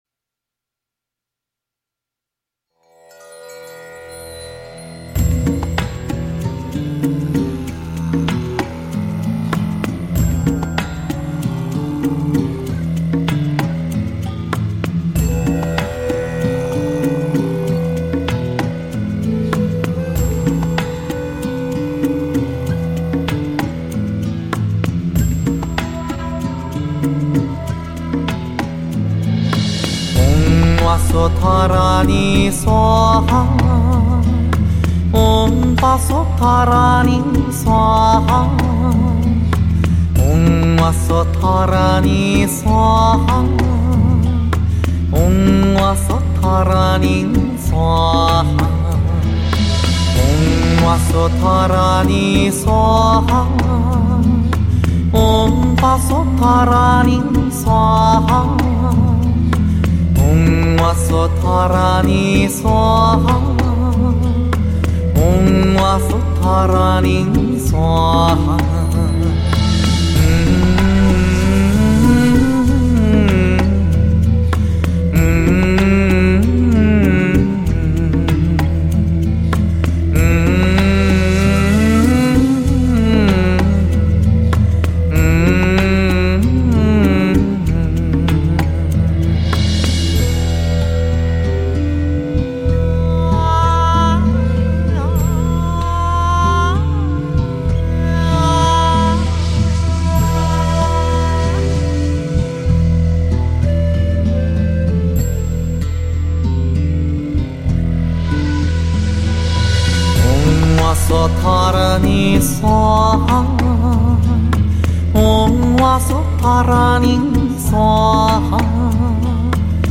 佛音 真言 佛教音乐 返回列表 上一篇： 陀罗尼(梵文